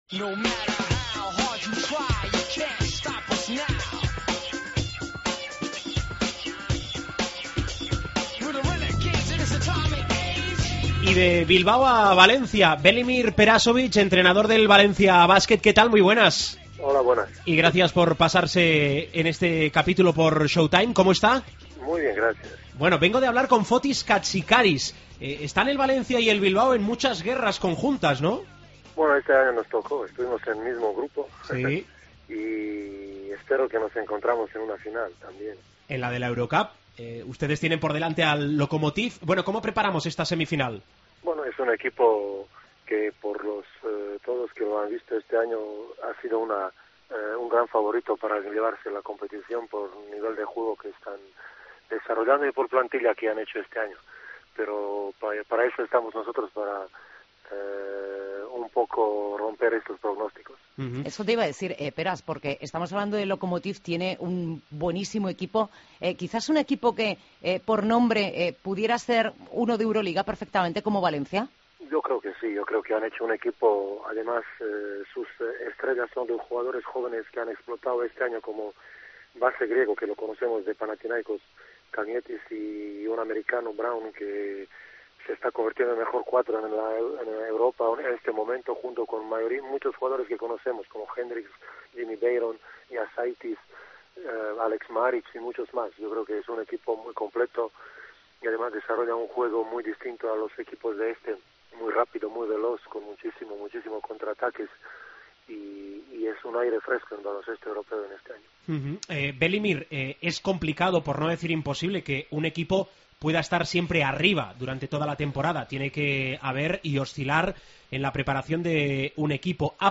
Entrevista a Velimir Perasovic, en Showtime